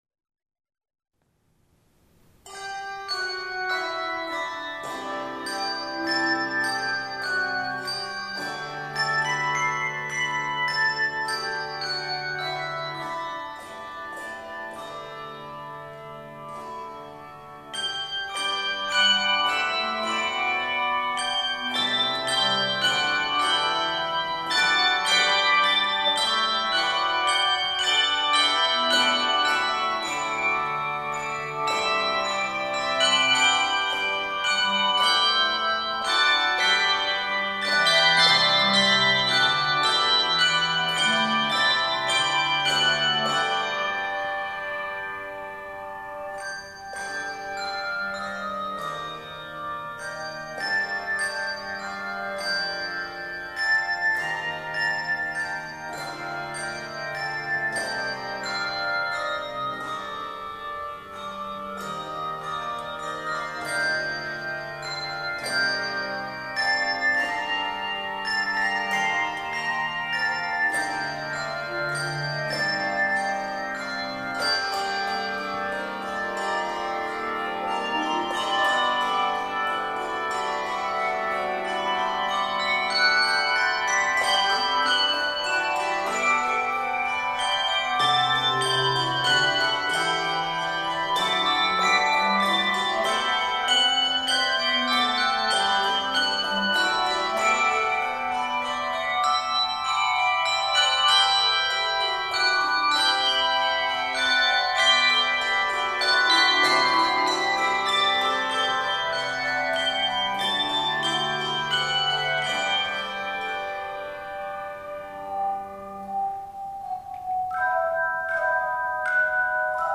Voicing: Handchimes